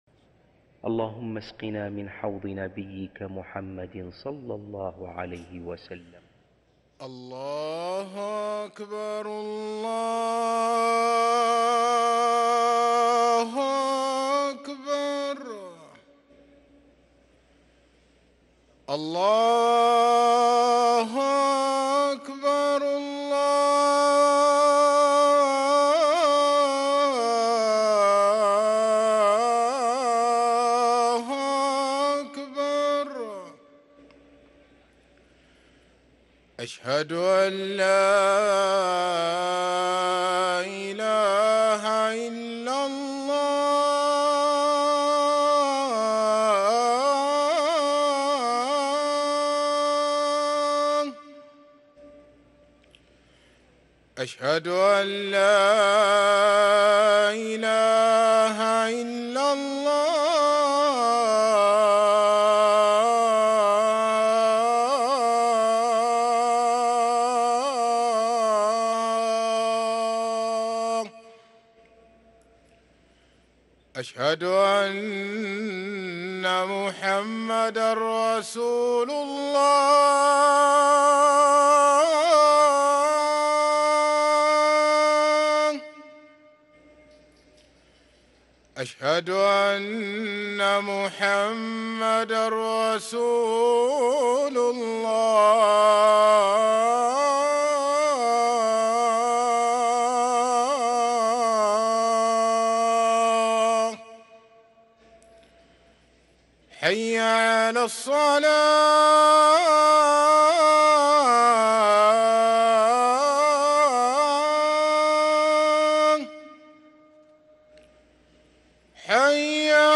أذان الظهر للمؤذن سعيد فلاته الاثنين 14 ربيع الأول 1444هـ > ١٤٤٤ 🕋 > ركن الأذان 🕋 > المزيد - تلاوات الحرمين